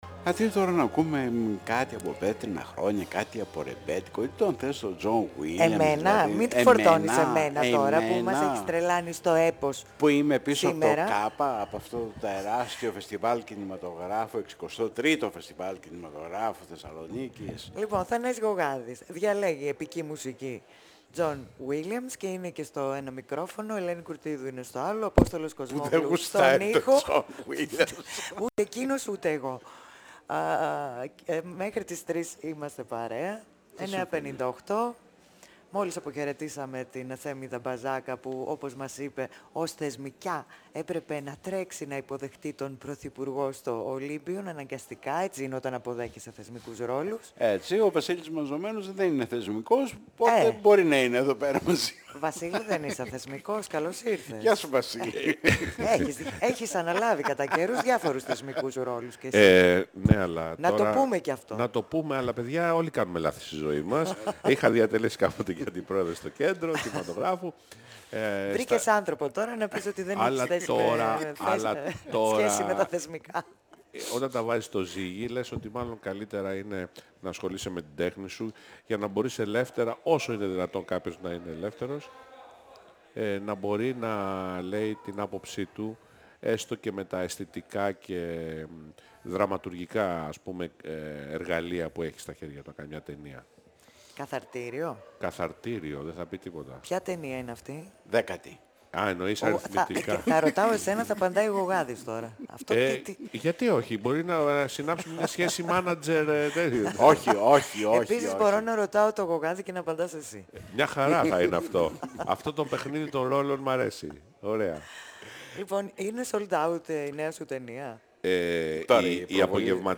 958FM Συνεντεύξεις